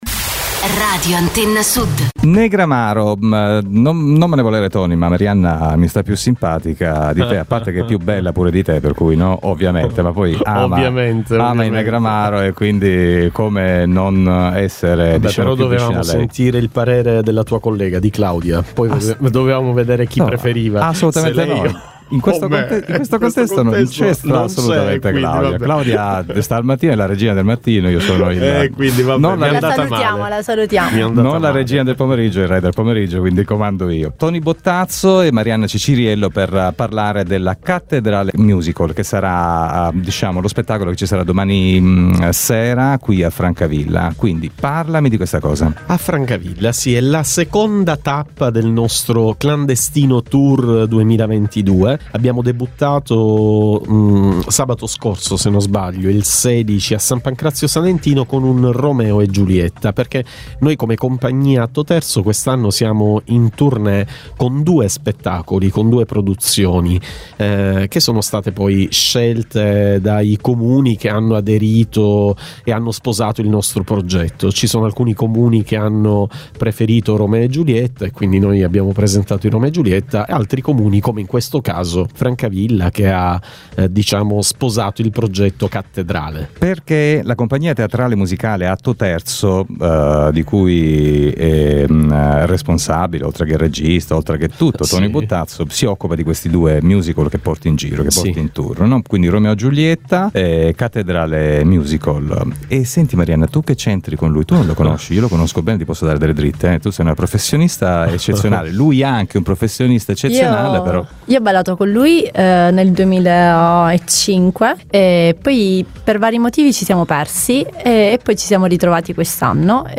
Una bella chiacchierata